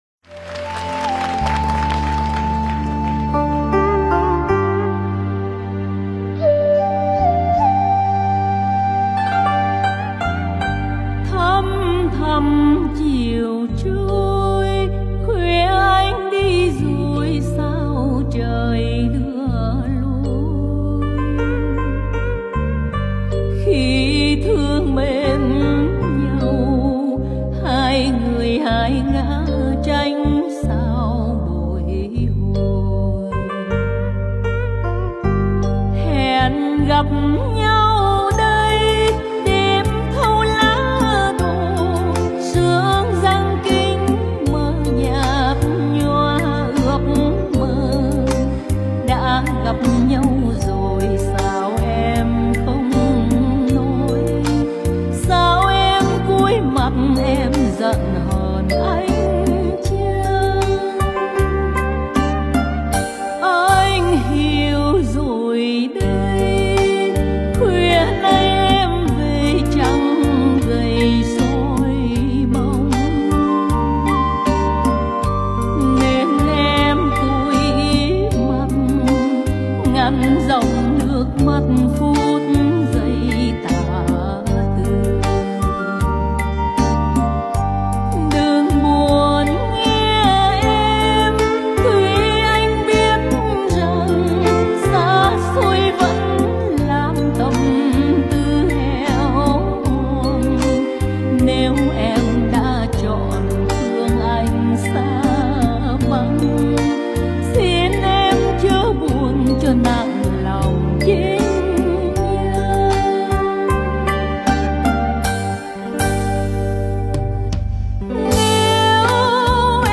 Nhạc Bolero Trữ Tình